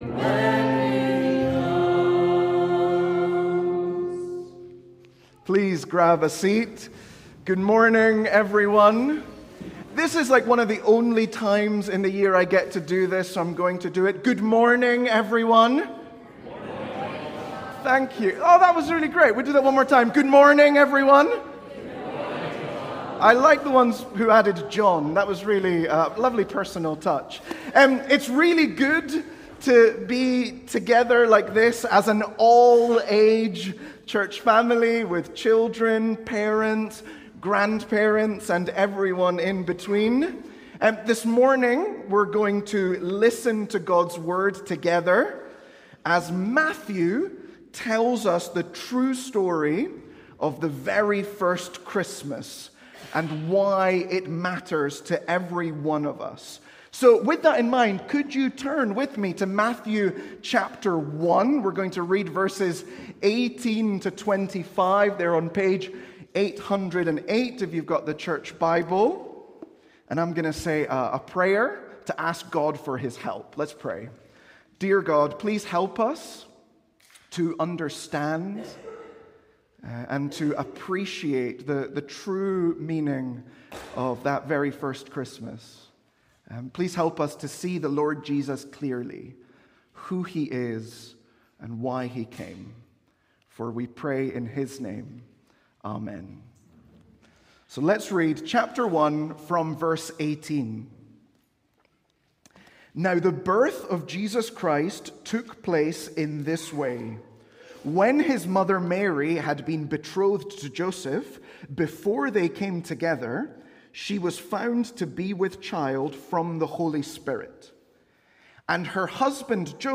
Sermons | St Andrews Free Church
All-Age Service